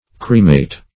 cremate - definition of cremate - synonyms, pronunciation, spelling from Free Dictionary
Cremate \Cre"mate\ (kr?"m?t or kr?-m?t"), v. t. [L. crematus, p.